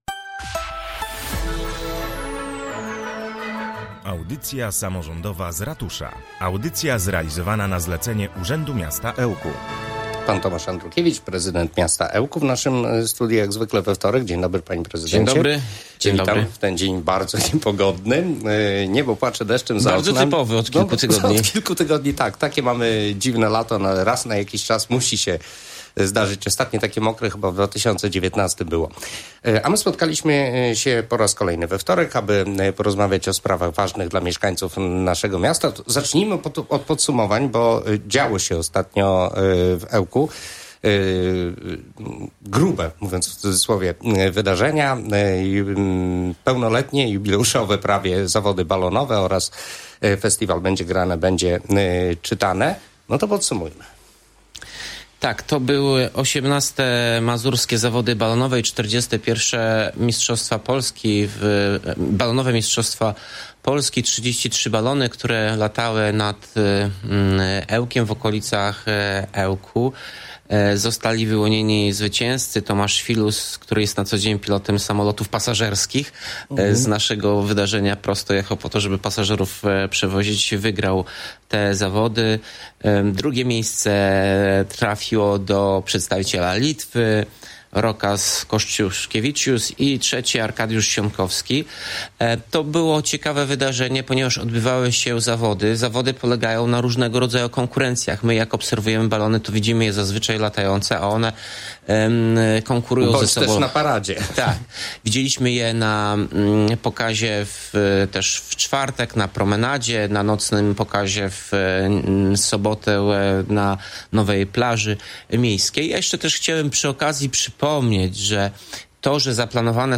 Mówił Tomasz Andrukiewicz, prezydent Ełku.
29.07-Audycja-z-Ratusza-Tomasz-Andrukiewicz.mp3